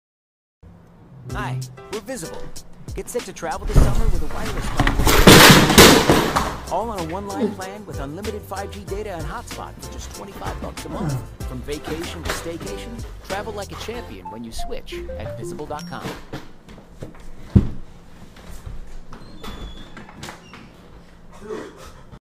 falls out of chair!